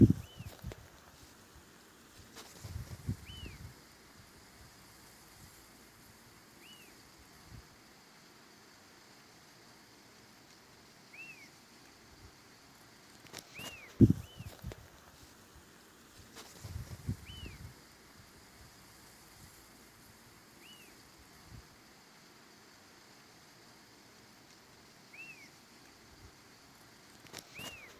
Fiofío Silbón (Elaenia albiceps)
Nombre en inglés: White-crested Elaenia
Localidad o área protegida: Altas cumbres
Condición: Silvestre
Certeza: Observada, Vocalización Grabada
fiofio-silbon.mp3